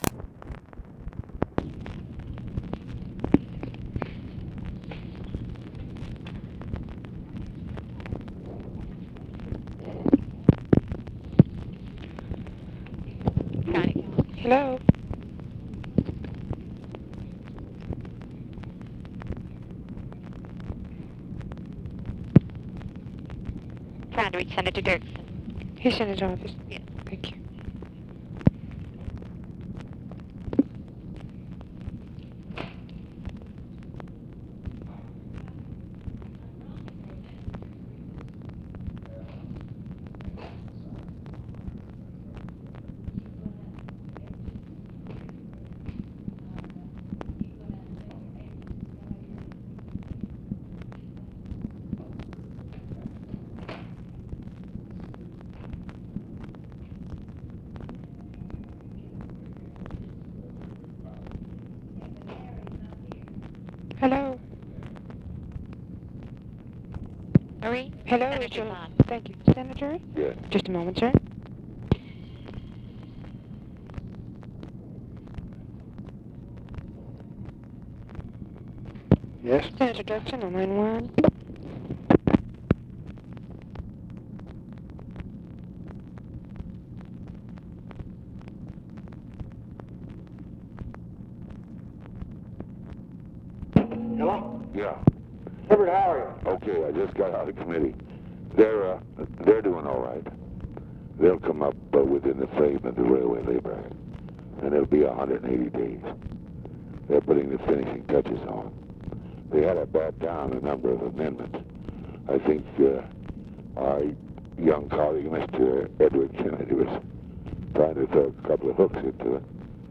Conversation with EVERETT DIRKSEN and OFFICE CONVERSATION, August 1, 1966
Secret White House Tapes